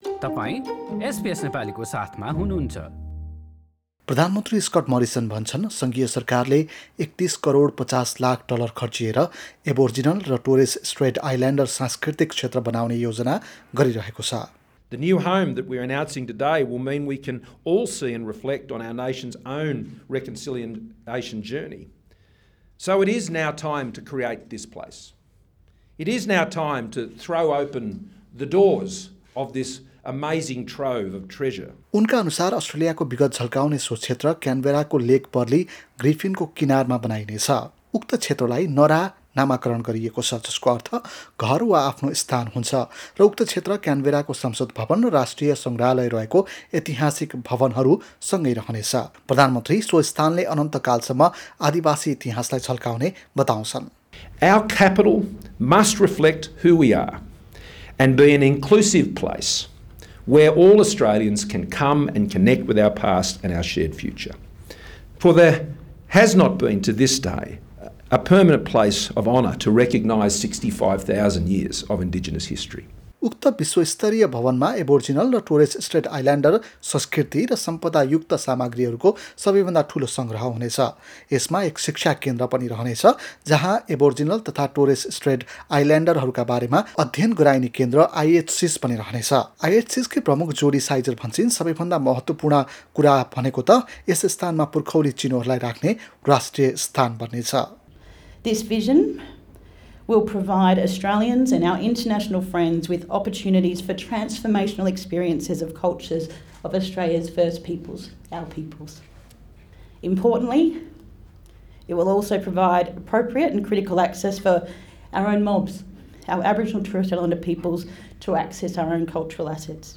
रिपोर्ट सुन्नुहोस्: अस्ट्रेलियाका आदिवासीहरू भन्छन् सरकारले केवल राजनैतिक फाइदाका लागि देखावटी काम मात्र गर्छ!हाम्रा थप अडियो प्रस्तुतिहरू पोडकास्टका रूपमा उपलब्ध छन्।